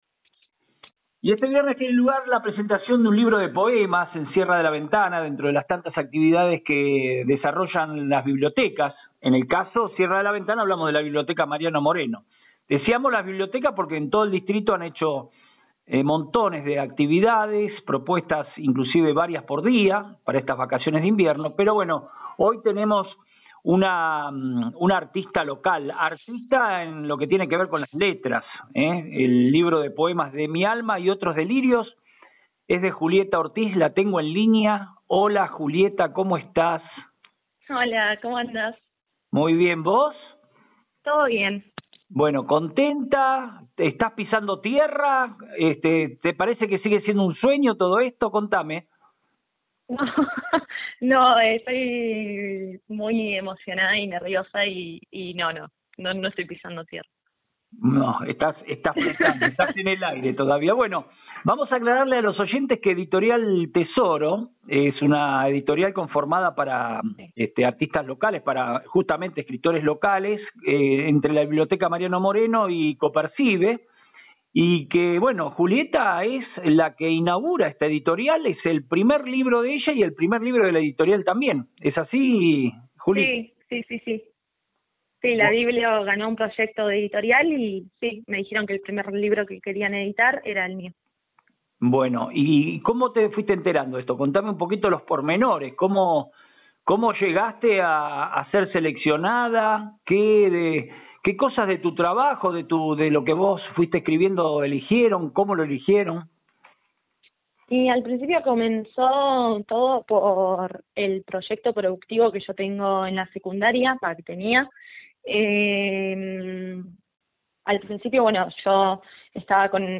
Esta mañana, conversamos con ella para invitar a toda la comunidad a la muestra que se realizará a partir de las 17:30 horas.